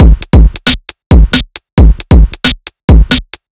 Overload Break 4 135.wav